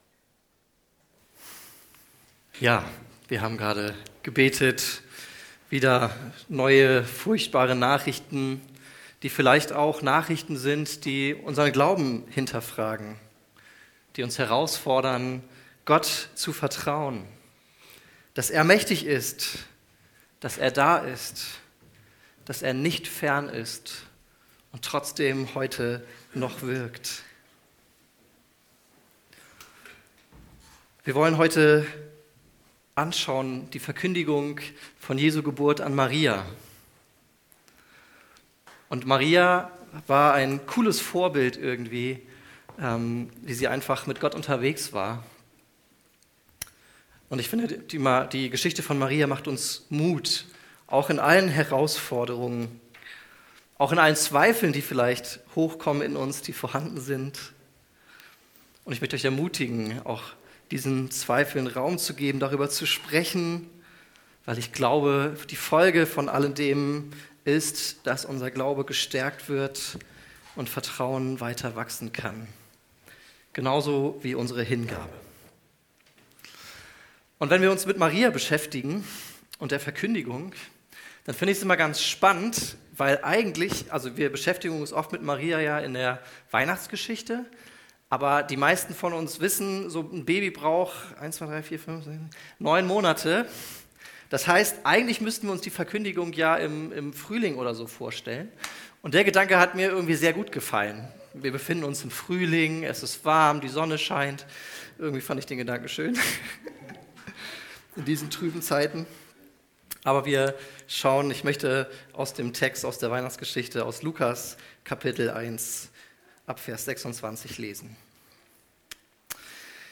Hingabe ohne Berechnung Prediger